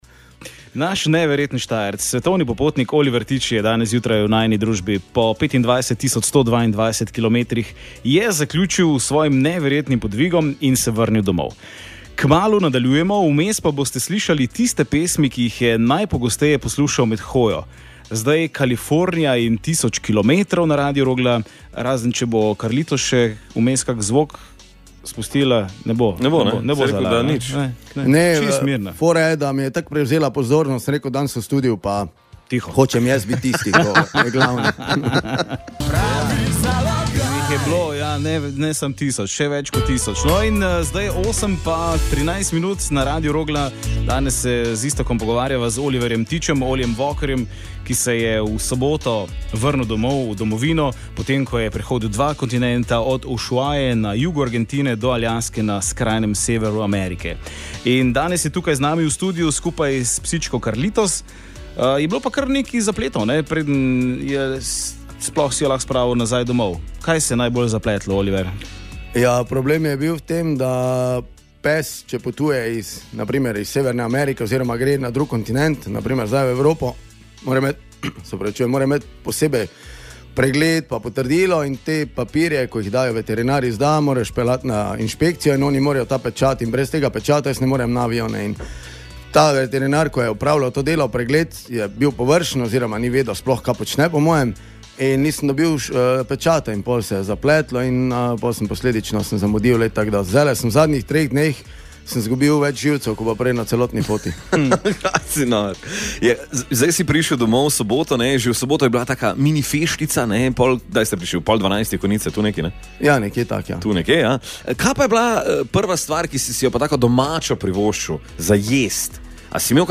Z neverjetnima popotnikoma smo preživeli ponedeljkovo jutro na Radiu Rogla.